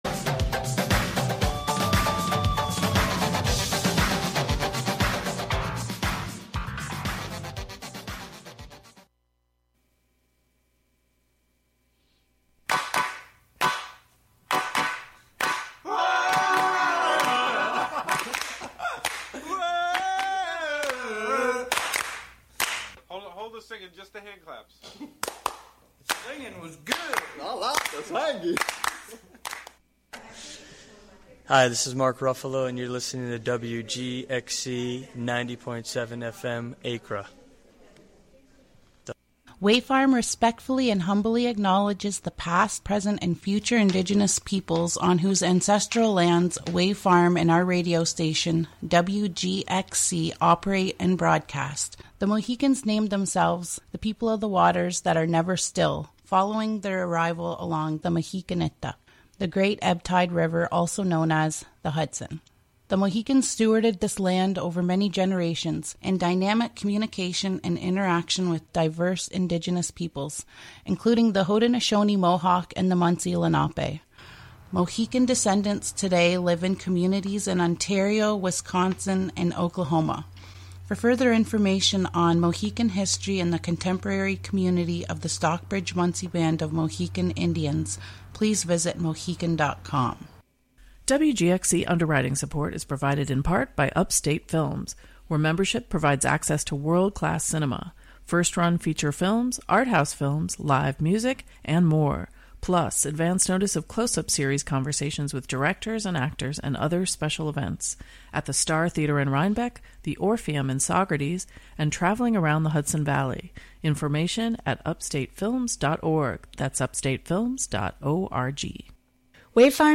From Tango to Flamenco and all Latinx music in between.